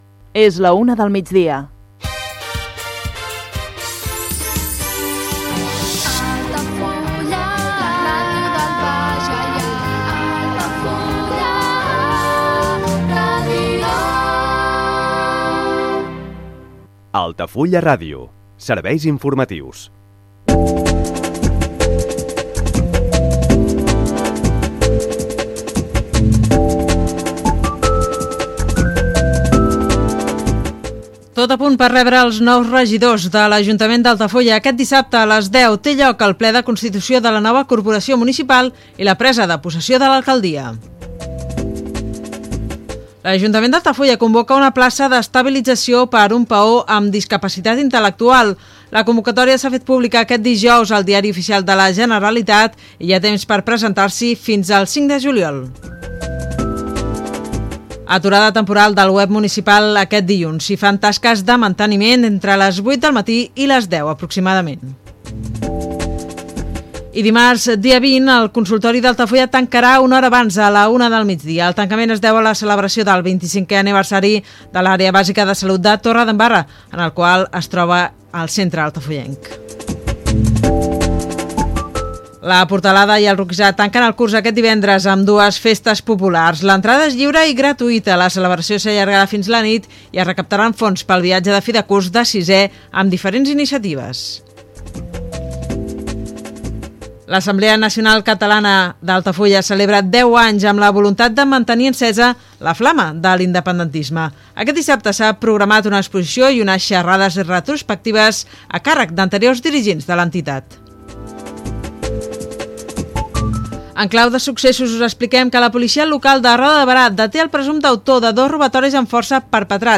Hora, indicatiu de l'emissora, sumari informatiu, publicitat, indicatiu de l'emissora, ple de constitució del nou consistori municipal.
Informatiu